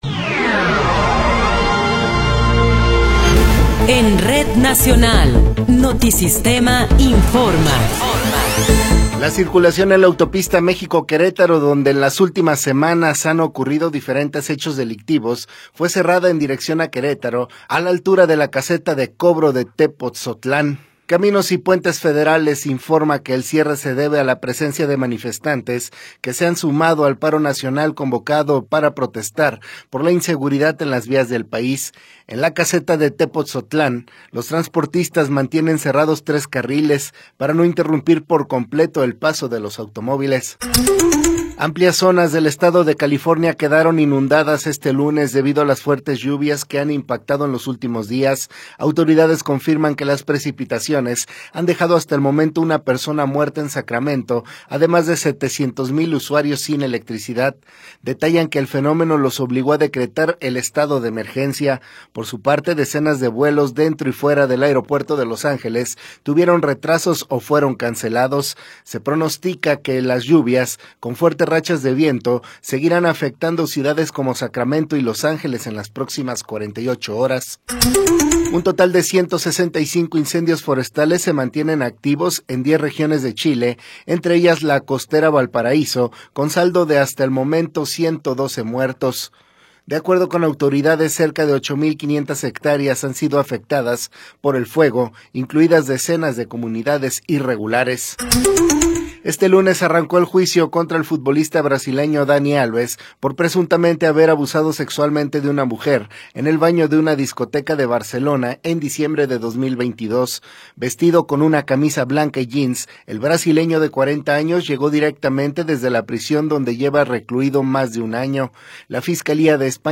Noticiero 11 hrs. – 5 de Febrero de 2024
Resumen informativo Notisistema, la mejor y más completa información cada hora en la hora.